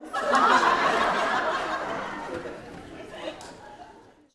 Audience Laughing-06.wav